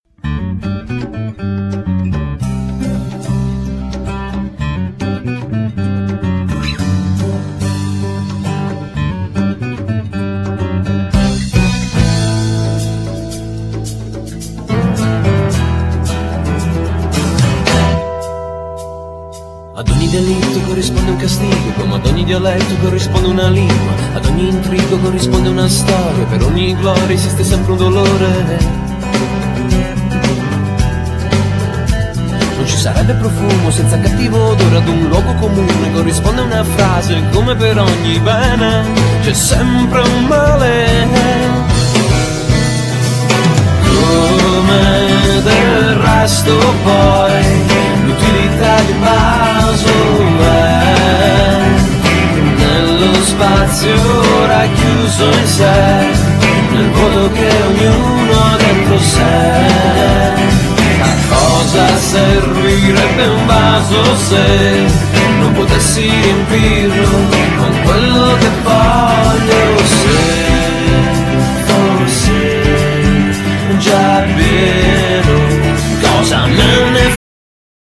chitarra e voce
clarinetto, basso e sax
percussioni e batteria
Genere : Pop / rock